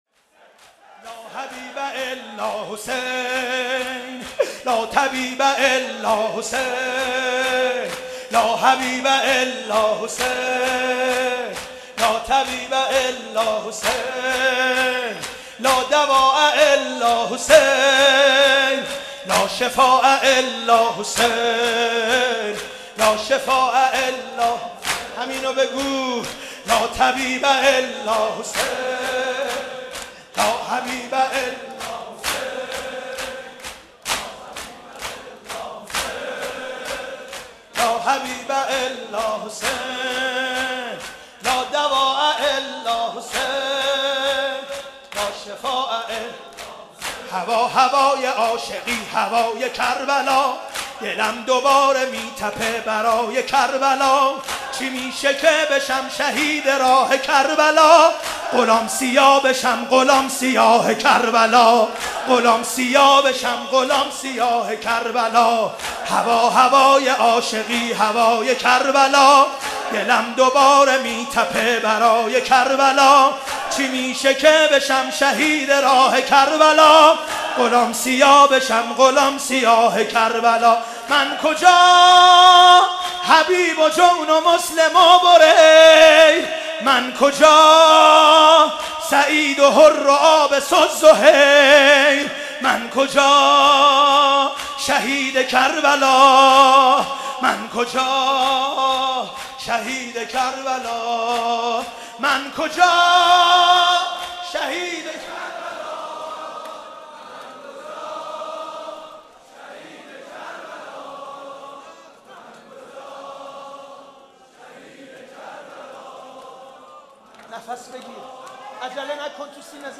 شب عاشورا محرم94_ شورزیبا_ لا حبیب الا حسین لا طبیب الا حسین